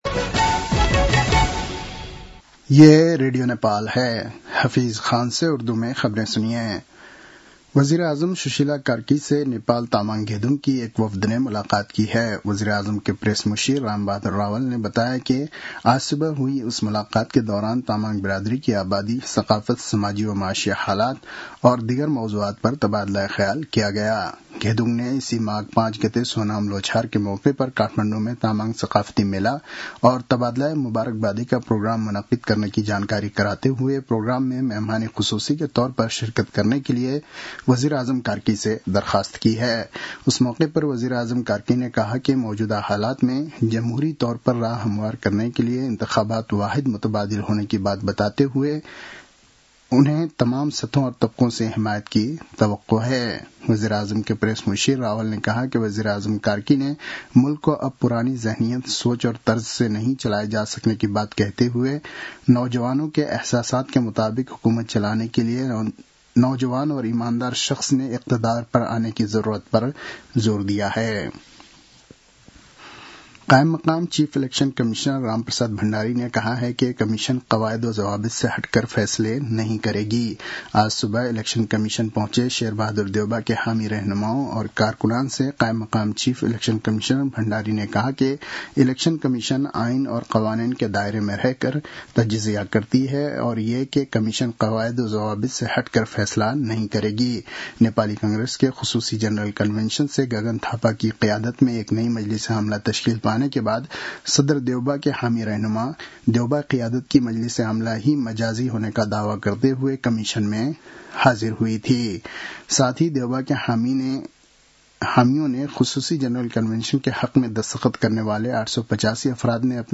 उर्दु भाषामा समाचार : २ माघ , २०८२
Urdu-news-10-02.mp3